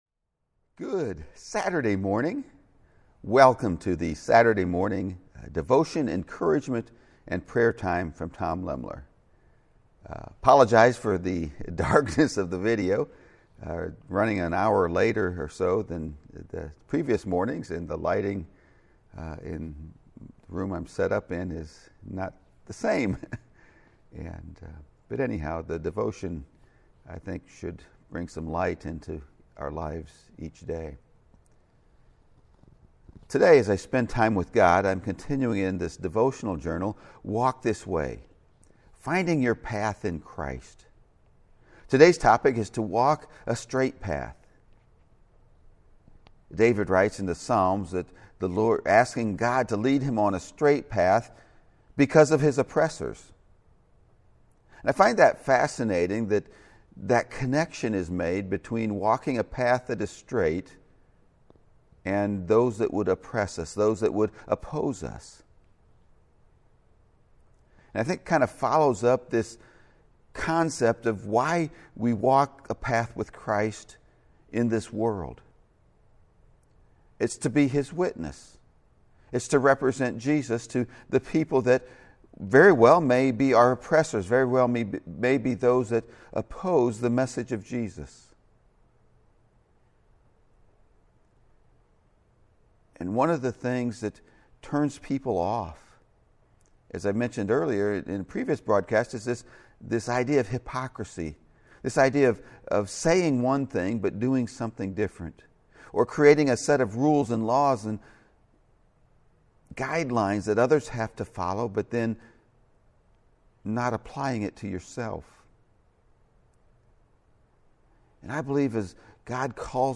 You can find the live video feeds of these encouragement and prayer times on Impact Prayer Ministry’s Facebook page and YouTube channel.